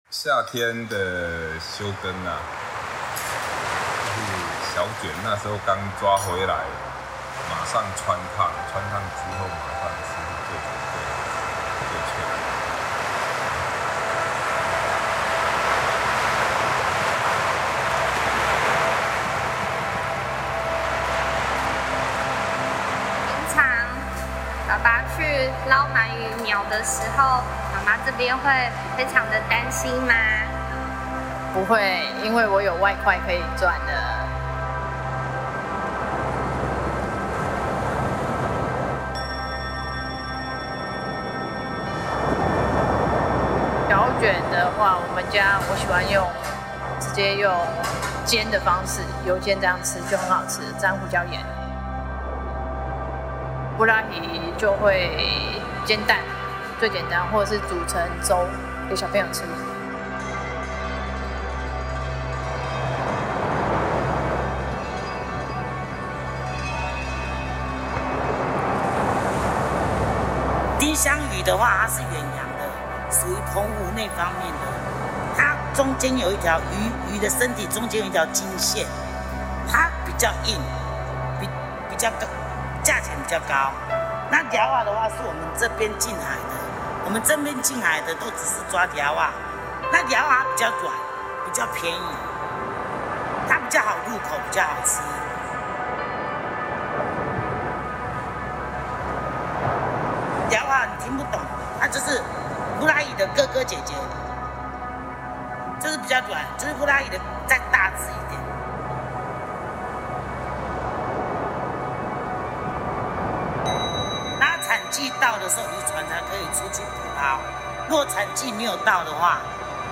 These tracks feature environmental recordings, oral histories, and storytelling, echoing the voices, rhythms, and transformations of coastal life in Jinshan.
藏海微語 Whispers Beneath the Surface: Quiet recollections from sea-foraging elders—stories of harvesting bī lê-á (sea snails) and observing environmental degradation, spoken in gentle tones as if underwater themselves.